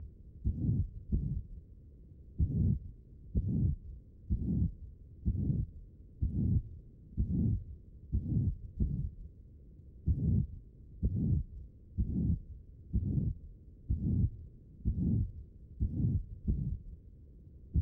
holosytolic murmur heard best at the apex and radiating into the axilla, what would be a likely pathogenesis for this problem?